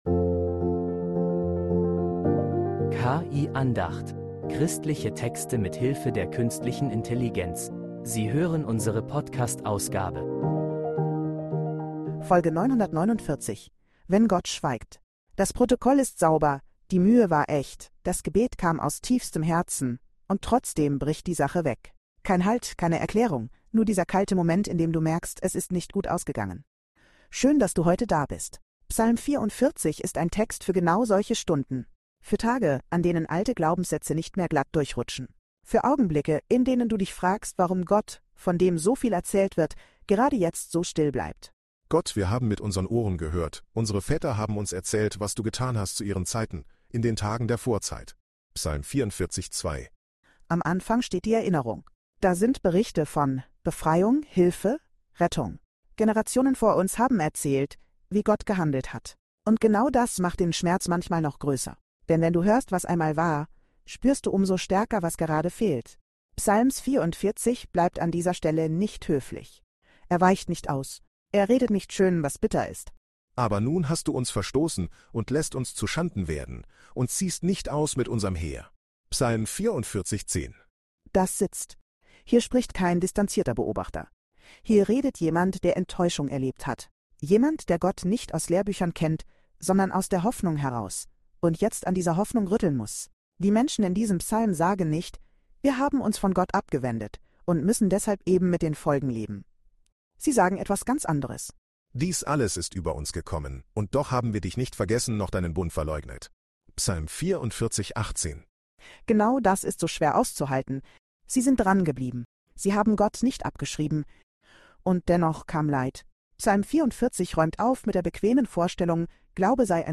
Eine Andacht über ehrlichen Glauben